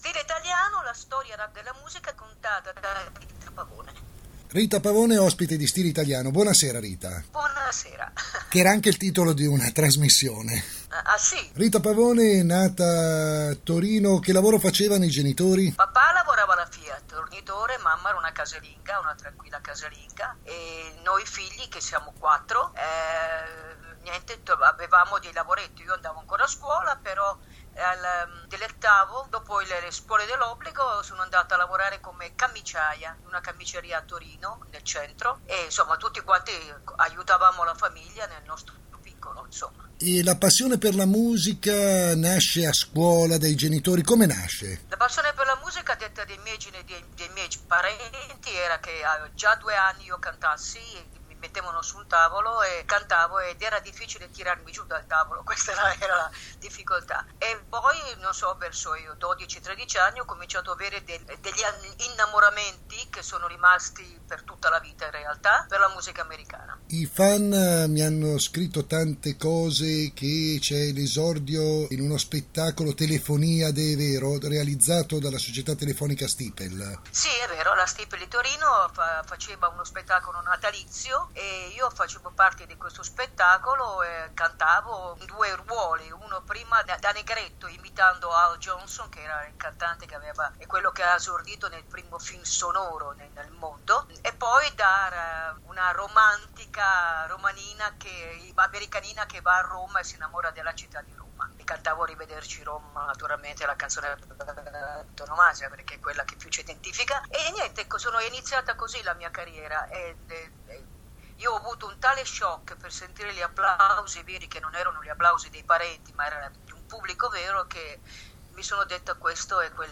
Ascolta la prima parte dell’intervista con Rita Pavone